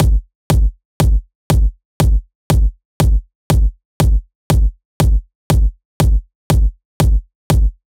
29 Kick.wav